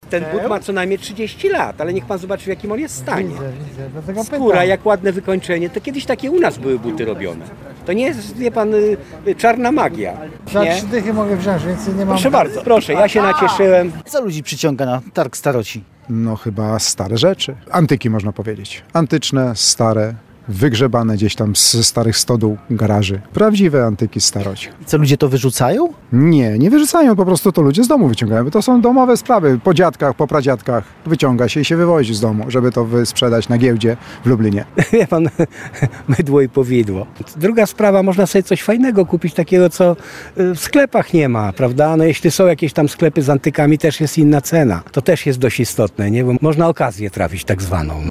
Prawdziwe antyki, starocie – mówi w rozmowie z Radiem Lublin mieszkaniec miasta.
Można trafić na tak zwaną okazję – dodaje.